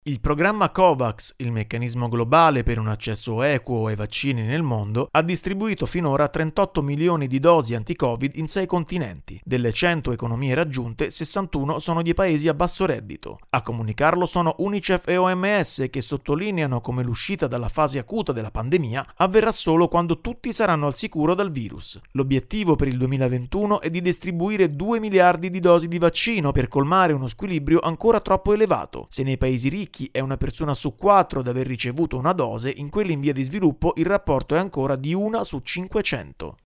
Il programma Covax ha raggiunto finora cento paesi nel mondo: ma per fermare il virus ancora non basta. Il servizio